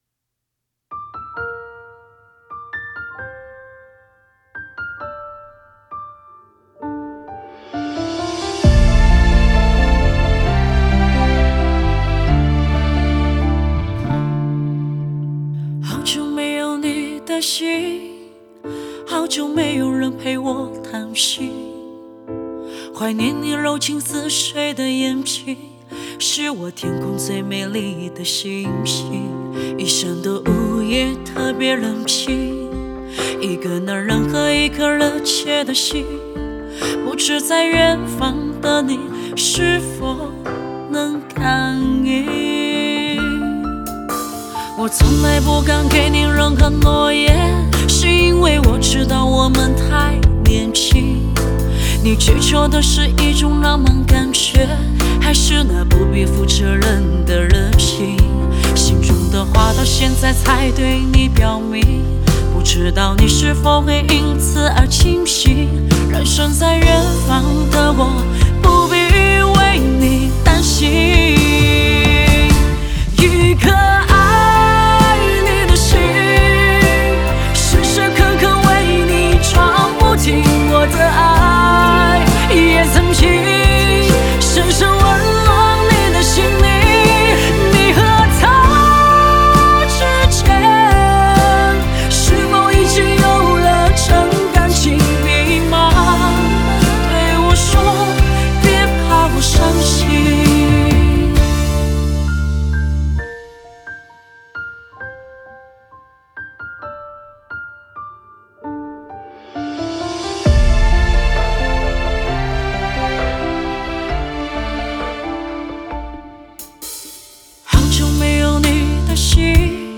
Ps：在线试听为压缩音质节选，体验无损音质请下载完整版
烟嗓版